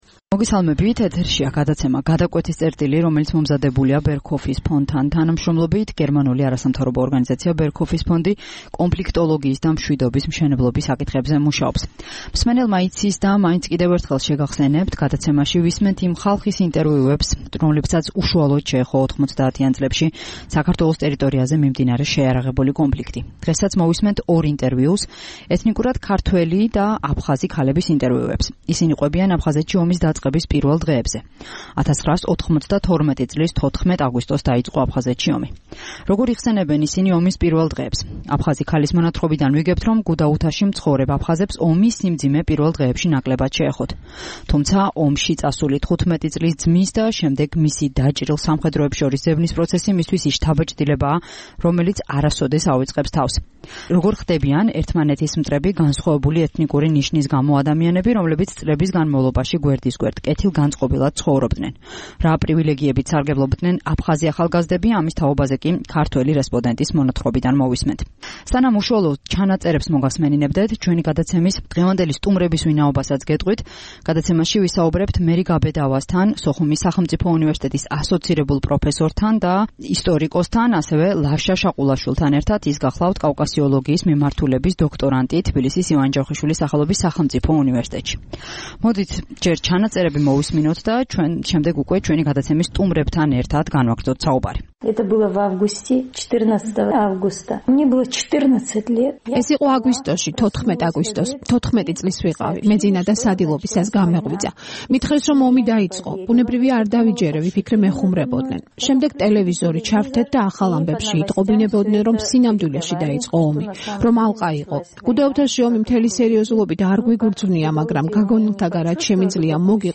გადაცემაში ორ ინტერვიუს მოისმენთ - ეთნიკურად აფხაზისა და ეთნიკურად ქართველის ინტერვიუებს. ისინი ჰყვებიან აფხაზეთში ომის დაწყების პირველ დღეებზე.